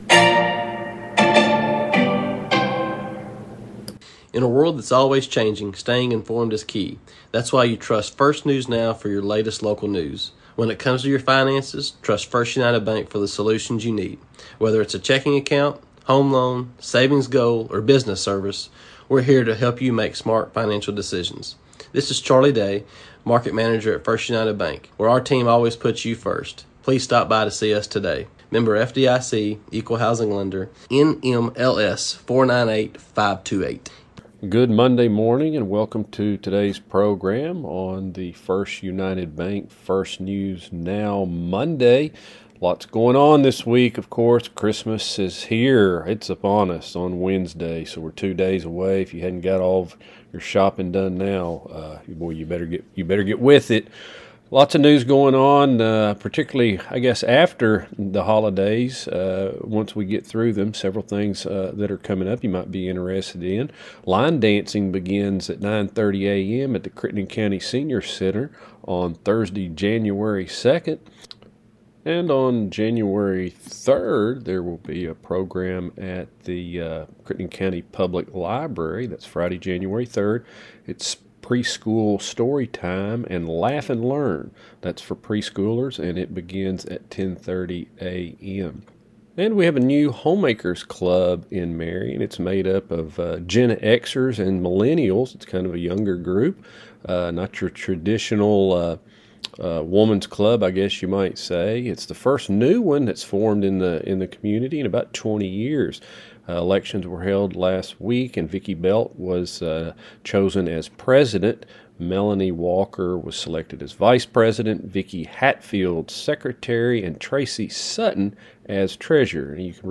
MONDAY NEWScast | One More Day until Christmas!